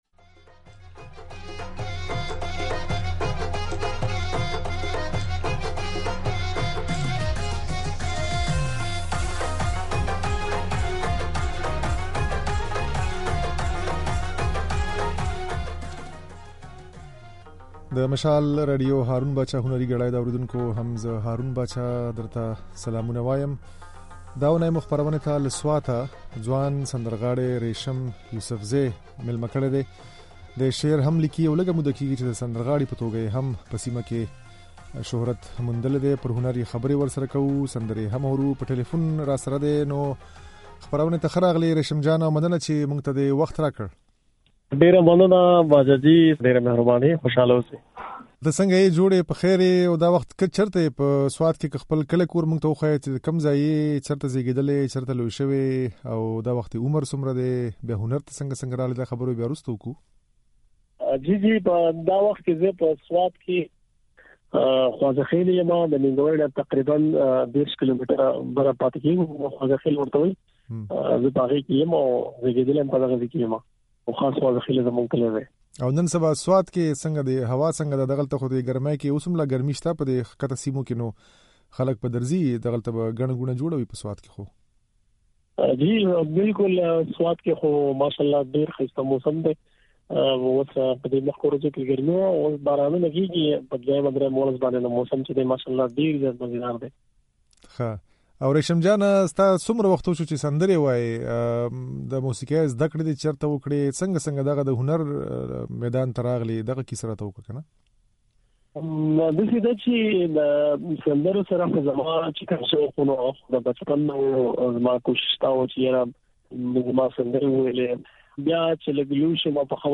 ځينې سندرې يې د غږ په ځای کې اورېدای شئ.